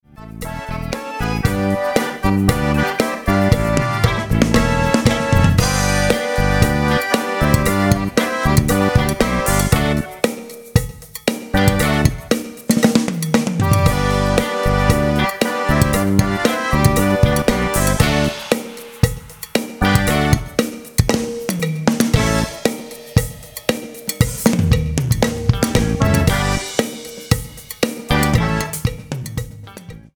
116 BPM